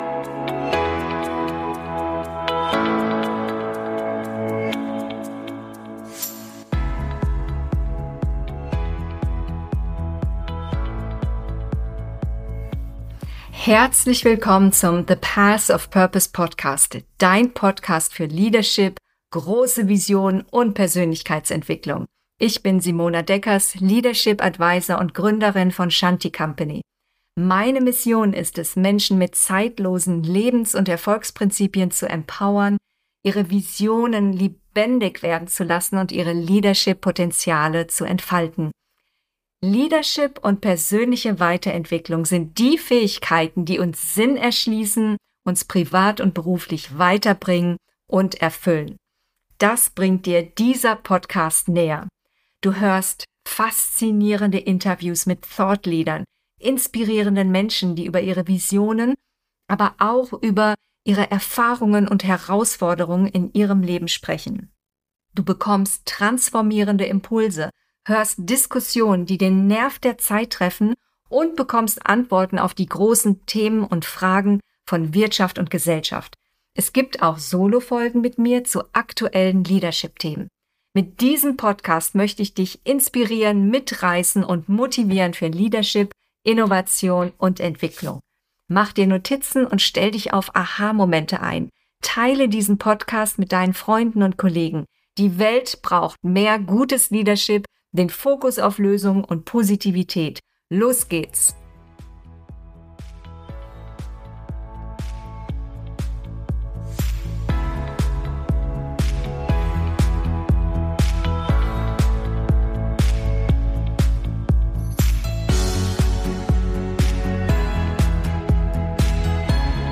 Revolution des Bildungssystems: die Zukunft der Bildung neu definiert - Interview